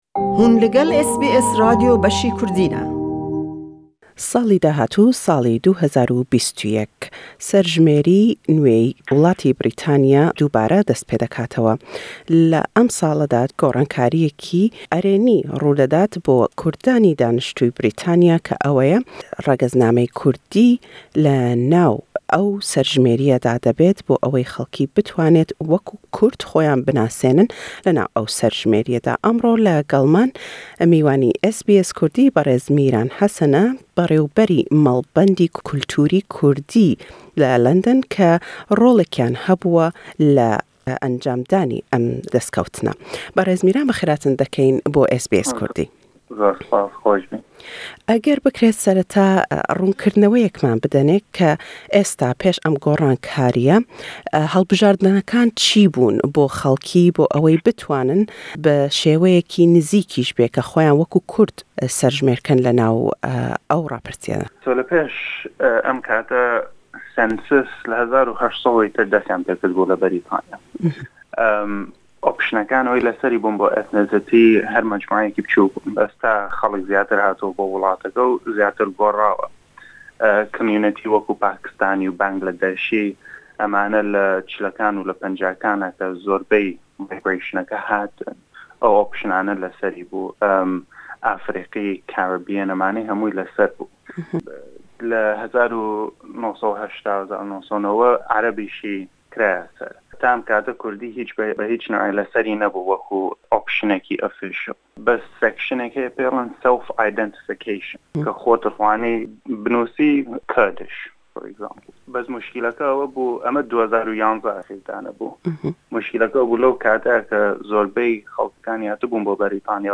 Bo yekem car pênaseyî regezî Kurdî le serjmêrî willatî Brîtaniya da debêt bo sallî 2021. le em lêdwane da